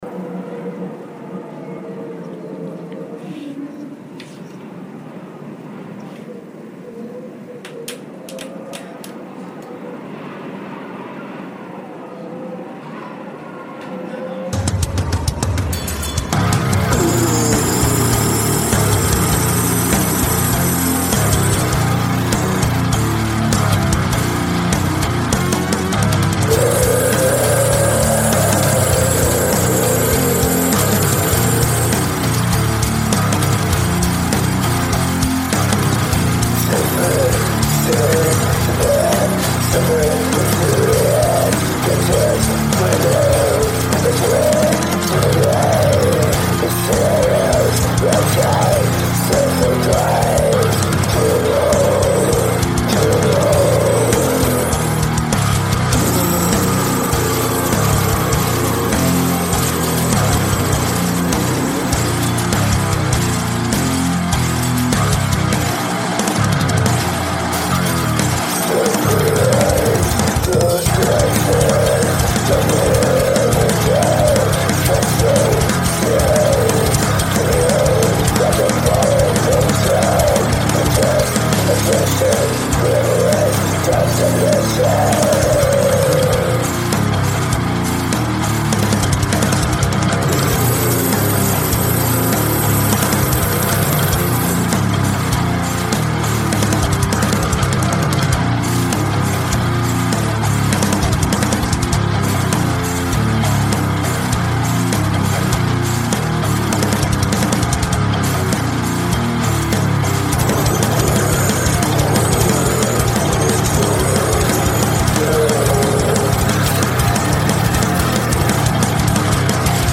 EstiloDeath Metal